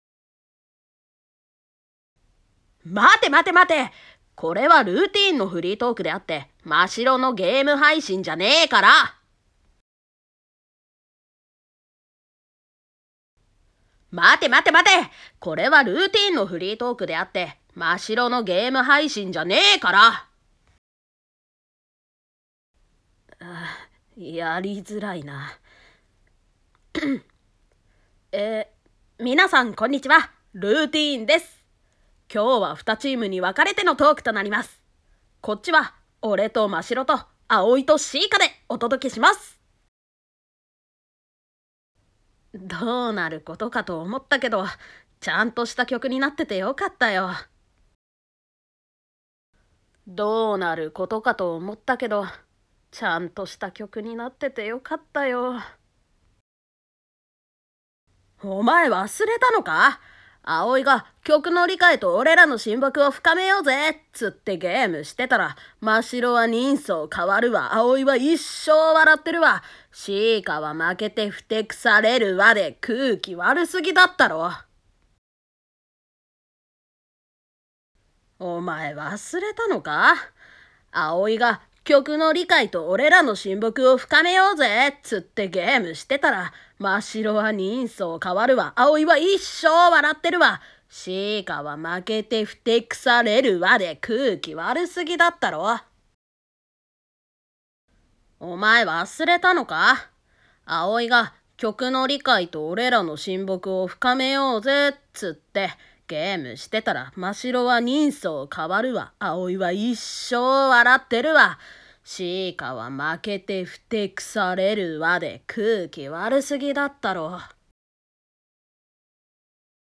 🔥声劇